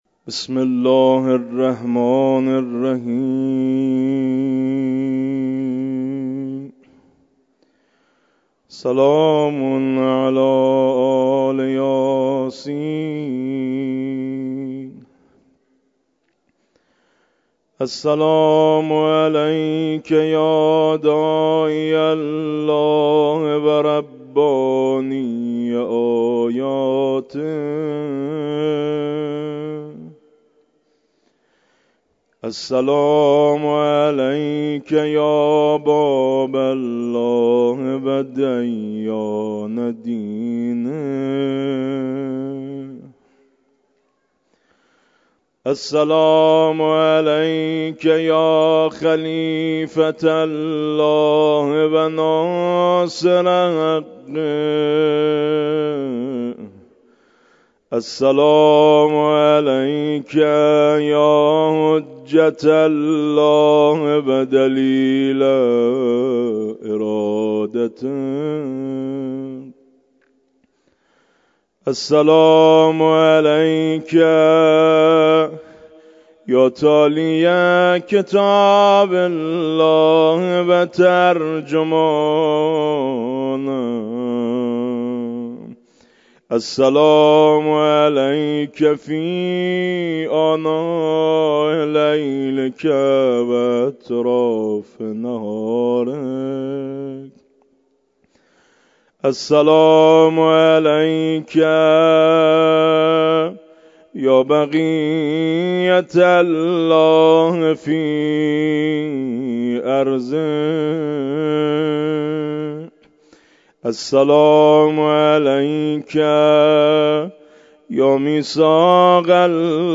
مراسم مسجد مقدس جمکران